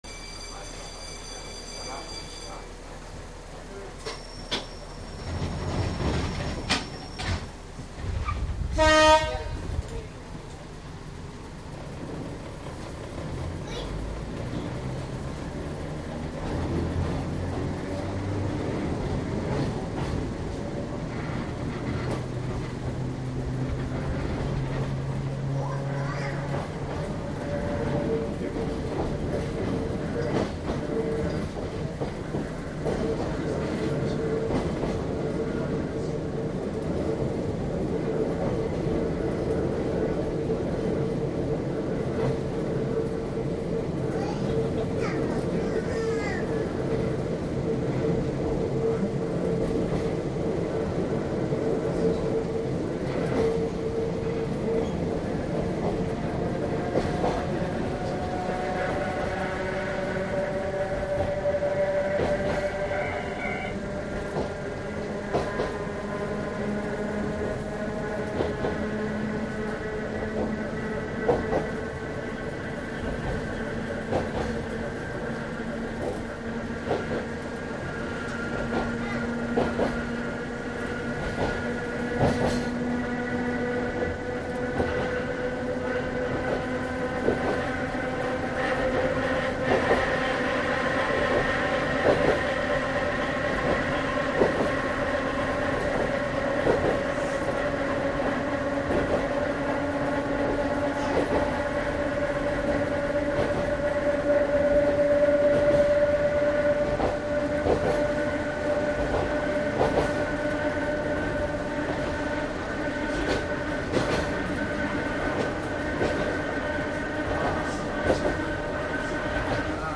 私が録音した車内走行音などです。
音声についてはこちらです。（五泉駅発〜村松駅着）
録音機材はＤＡＴウォークマン（ＴＣＤ−Ｄ８）とマイク（ＥＣＭ−９５９Ａ）です。風雑音が若干ありますが、唸る吊り掛けモーターと旧型のコンプレッサーの音をお楽しみ下さい。低音がよく出るスピーカーやヘッドフォンがあるとより楽しめるかも知れません。